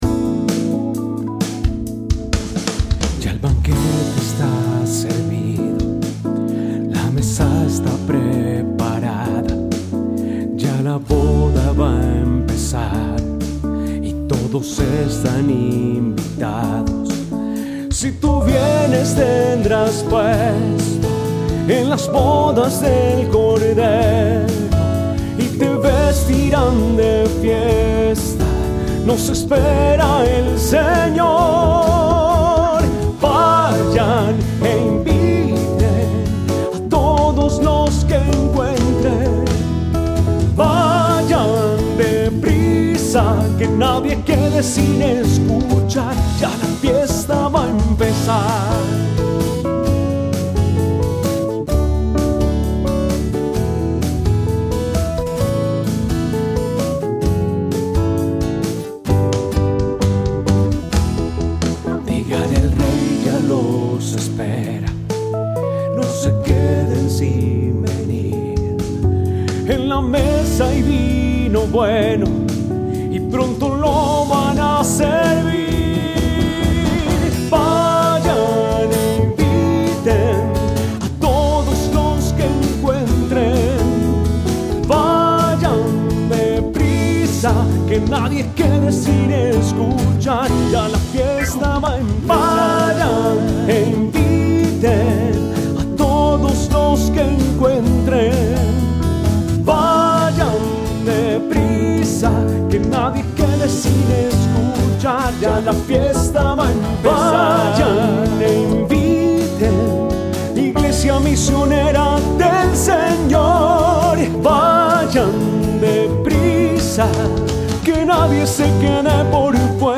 Creación de un himno para la jornada